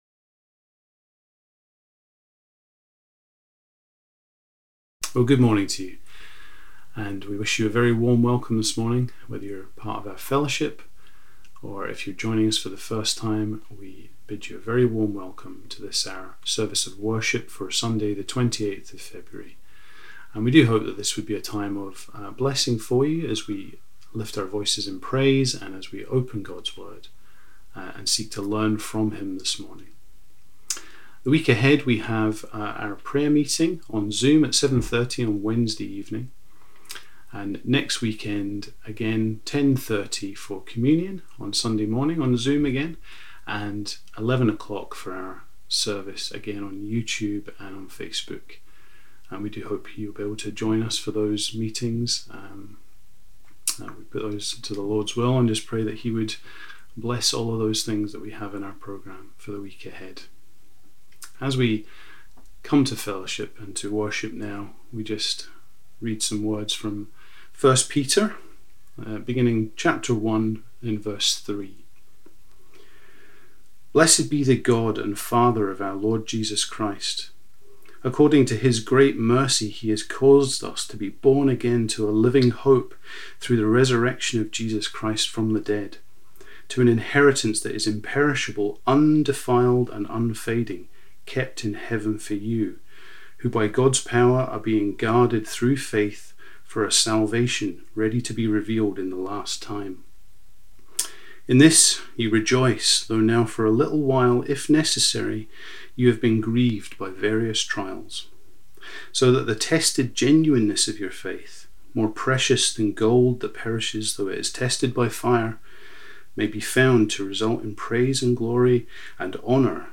Morning Service 28th February 2021 – Cowdenbeath Baptist Church